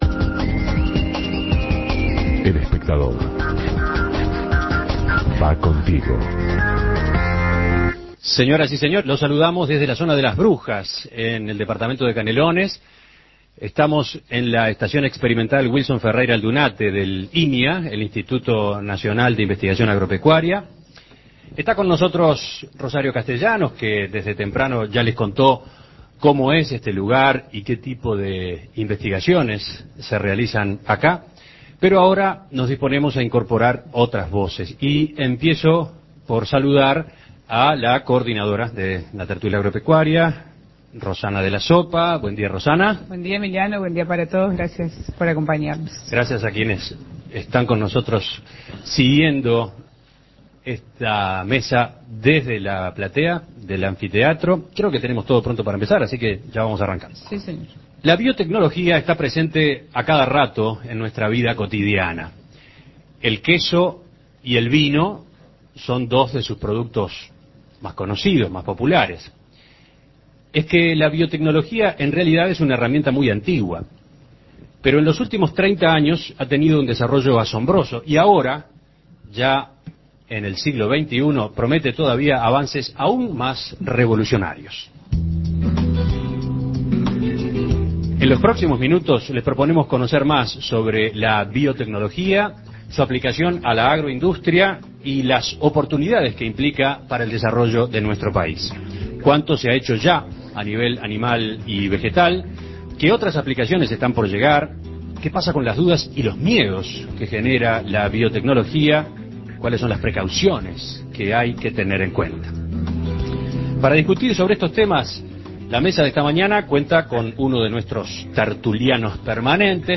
La biotecnología es una herramienta que se utiliza en diferentes sectores productivos desde hace varios años. ¿Qué aplicaciones existen en el agro y qué potencialidades implica para el desarrollo del país? Para discutir el tema, la Tertulia Agropecuaria se trasladó hasta la Estación Experimental Wilson Ferreira Aldunate, del INIA, en la zona de Las Brujas, Canelones